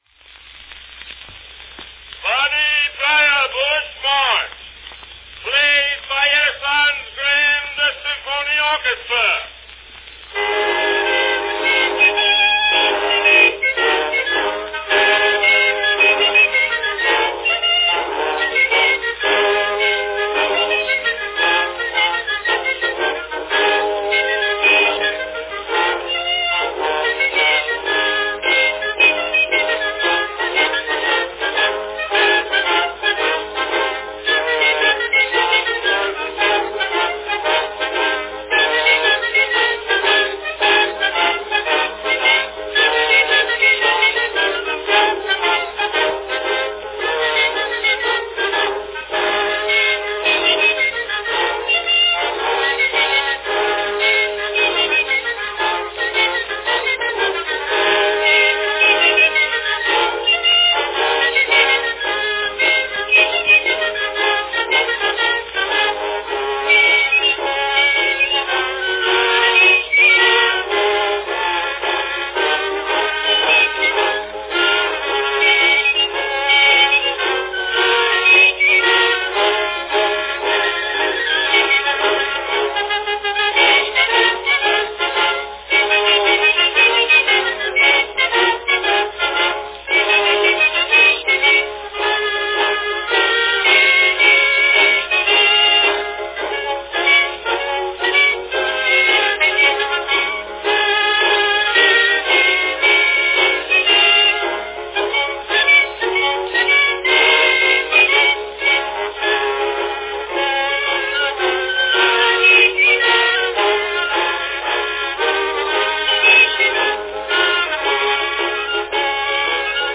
From 1899, Edison's Grand Symphony Orchestra plays the snappy Bonnie Brier Bush March.
Cylinder # 511
Category Orchestra
Performed by Edison Grand Symphony Orchestra